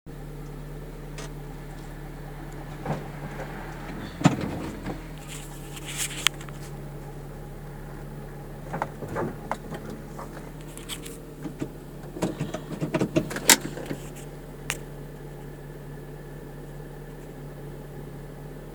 B.N.I (bruit non identifié)
Étrange ton bruit!!!